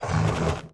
Index of /App/sound/monster/ice_snow_dog
walk_1_1.wav